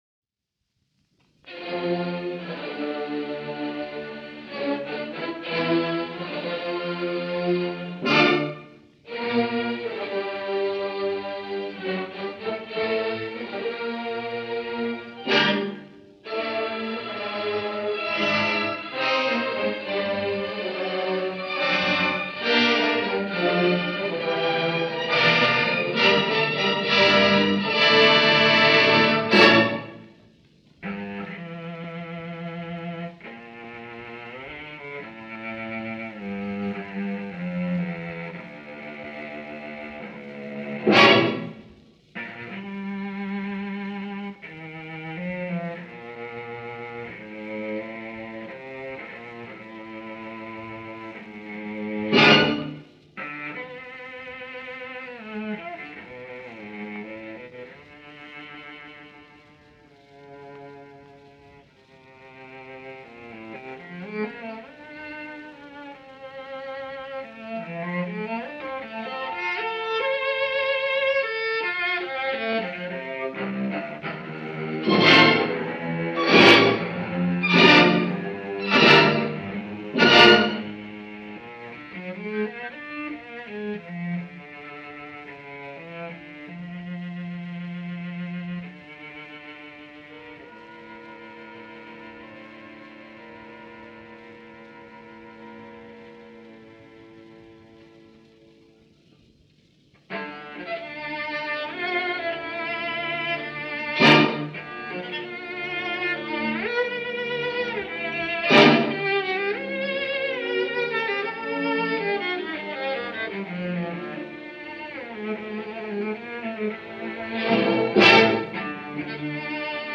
Over to Paris this weekend for a Radio studio recording of the Lalo Cello Concerto, with the legendary Andrè Navarra, cello and the Paris Conservatory, conducted by the great Andrè Cluytens.
Andrè Navarra – one of the more eloquent exponents of French Cello playing.